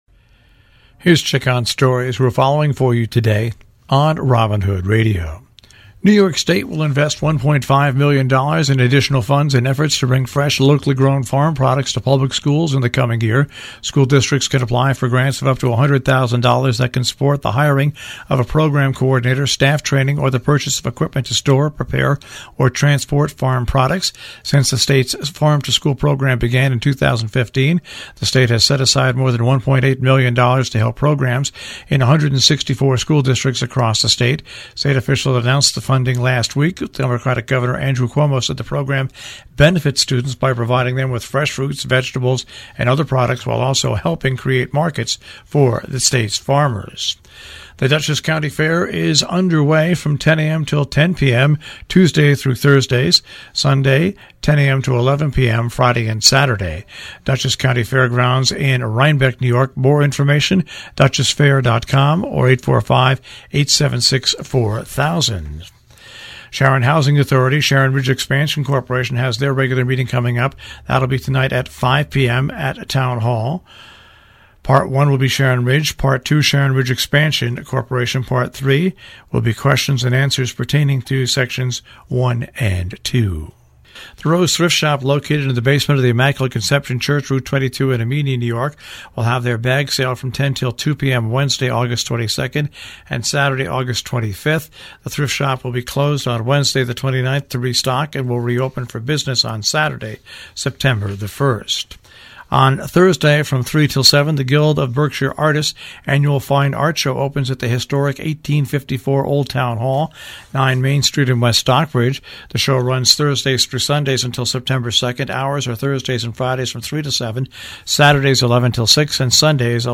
WHDD Breakfast Club News